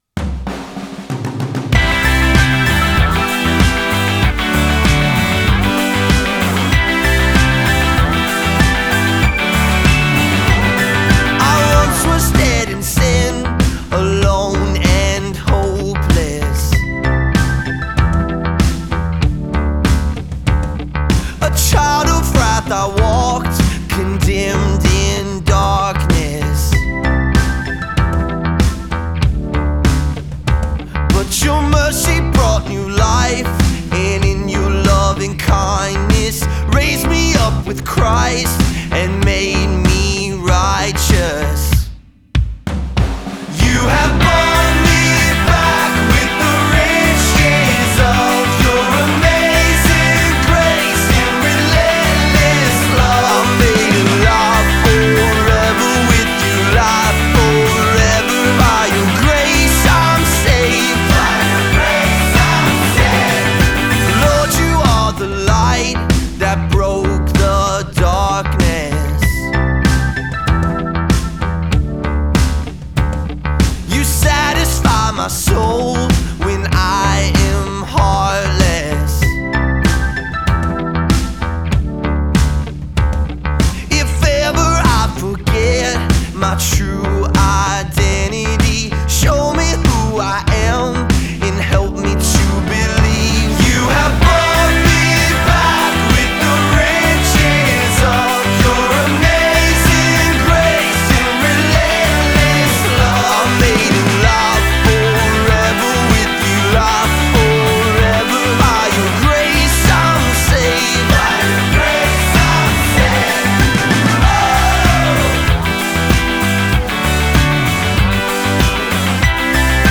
[1] Turns out it was a Christian band.